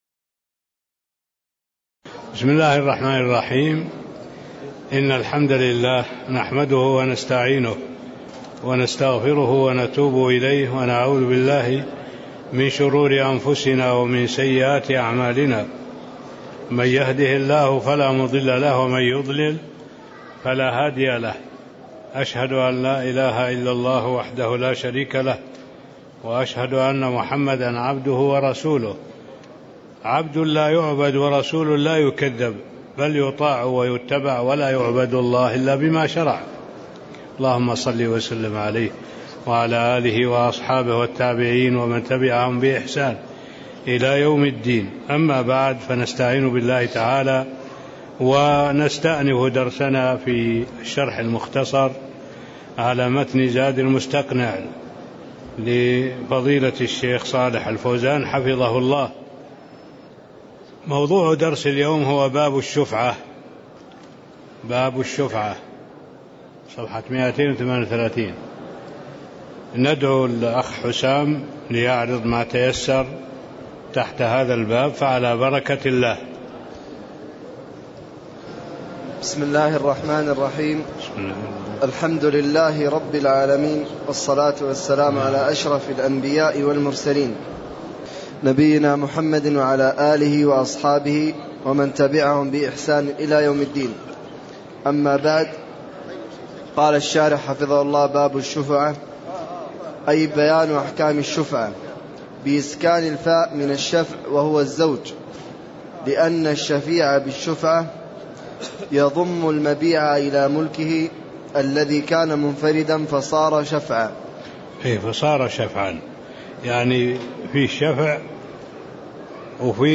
تاريخ النشر ٧ جمادى الأولى ١٤٣٥ هـ المكان: المسجد النبوي الشيخ